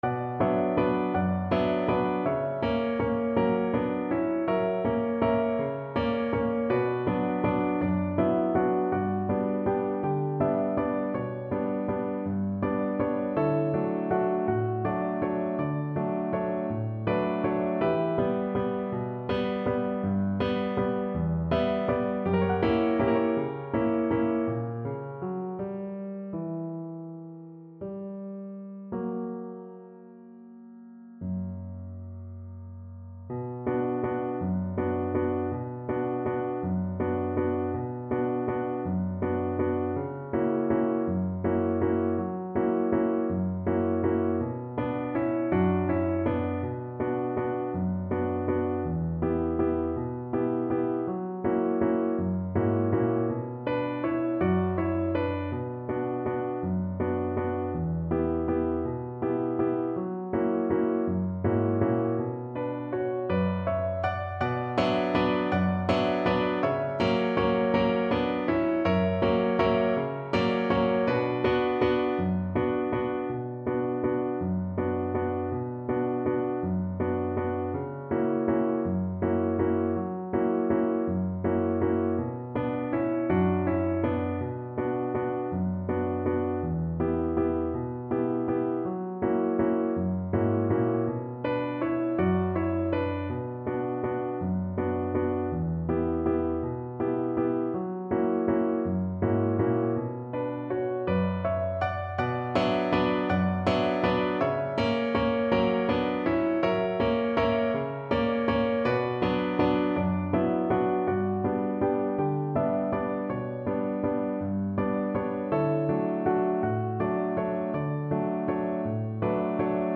3/4 (View more 3/4 Music)
One in a bar .=c.54
Classical (View more Classical Violin Music)